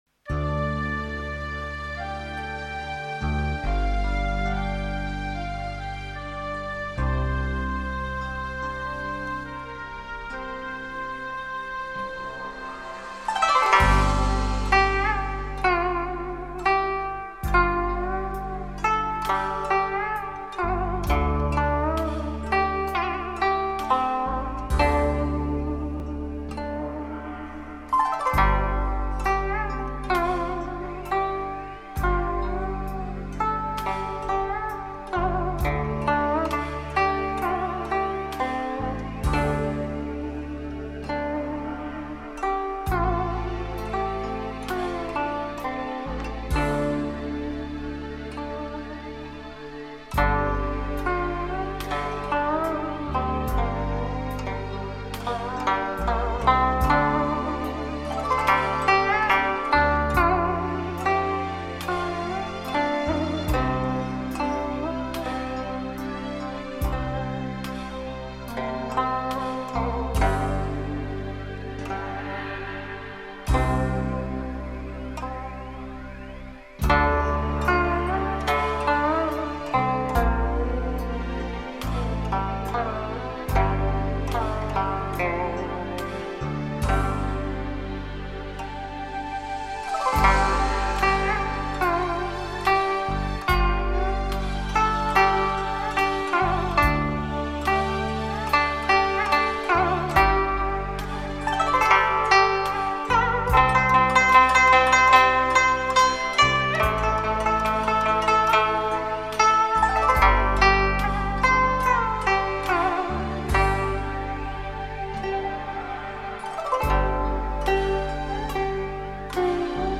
汇聚中国最具代表的古筝演奏作品
古筝的音色，清晰如水，飘然若云，急缓有致的乐音给人清透心扉的淡雅之美，融入合成器的空灵音质，更显悠渺韵致
无损高保真音色